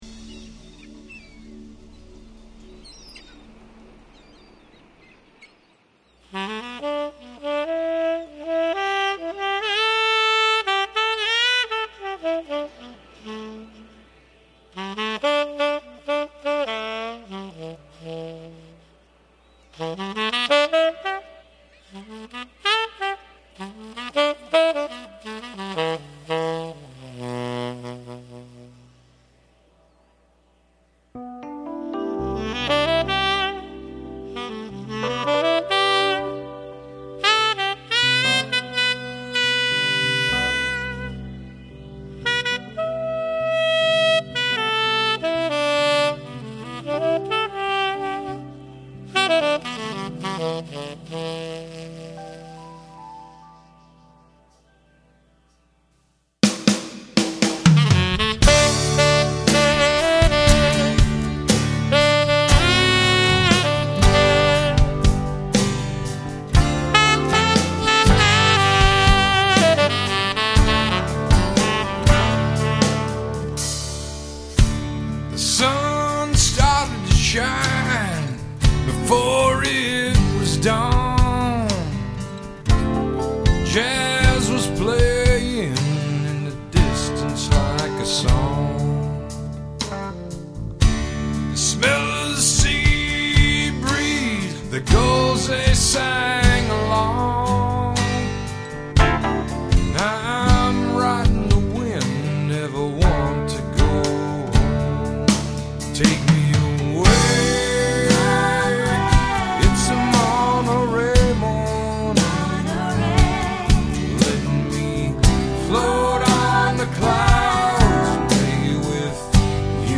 RECORDED at Arcade Digital Studios, Tumwater, WA
Guitars
Keyboards
Drums